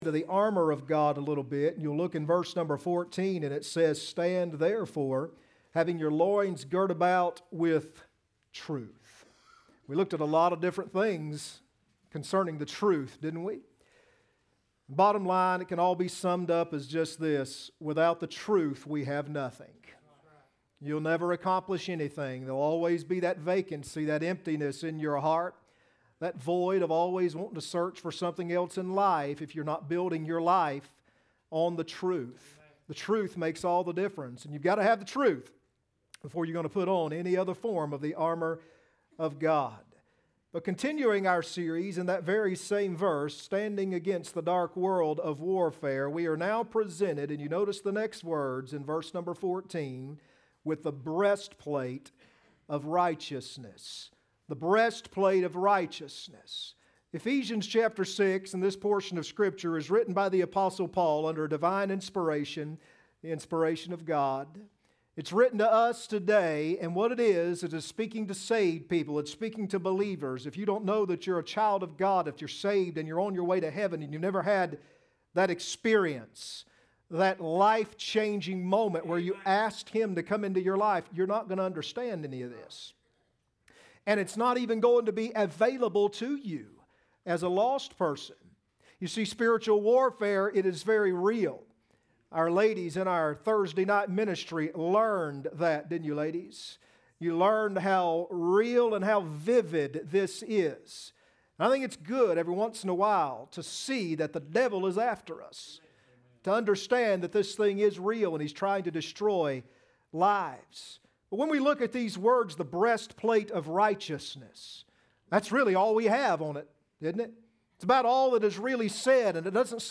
Ephesians 6:14 Service Type: Sunday Morning Next Sermon